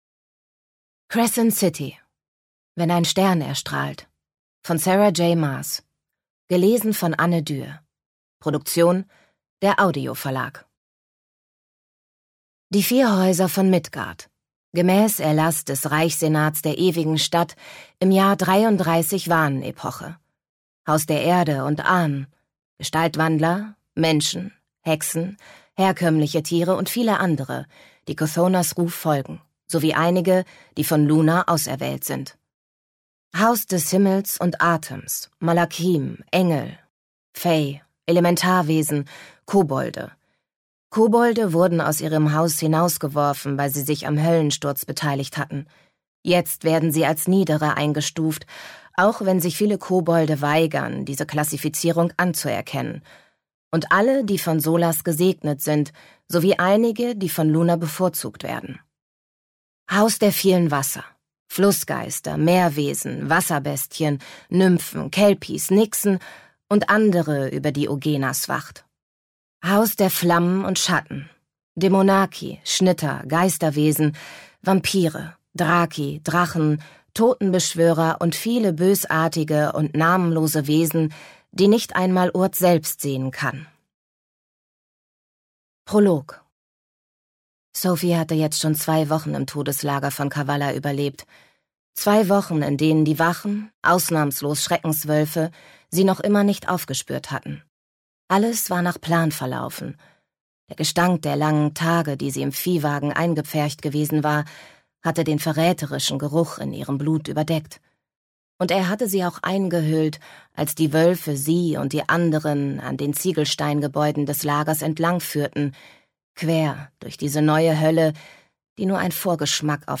Crescent City – Teil 2: Wenn ein Stern erstrahlt Ungekürzte Lesung